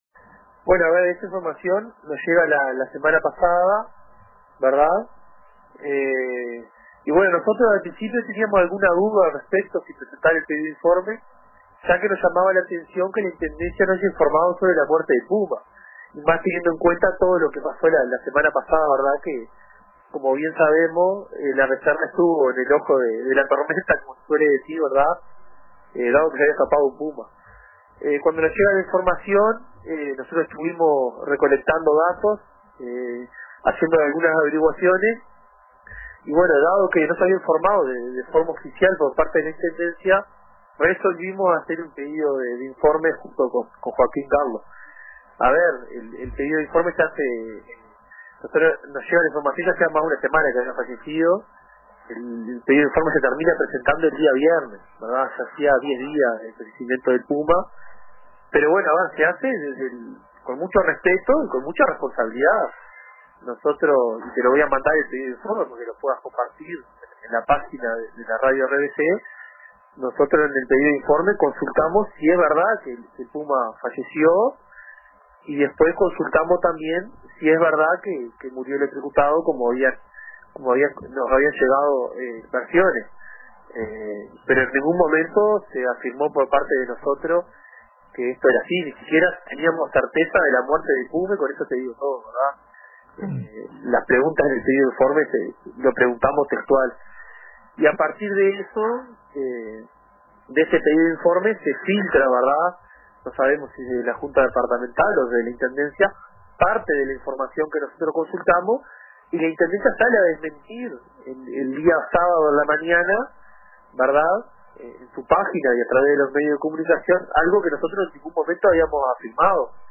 El edil departamental Juan Urdangaray (FA) aclaró en el programa Radio con Todos de RBC que nunca afirmó que el puma fallecido en la ECFA hubiera muerto electrocutado, como se sugirió en algunos rumores.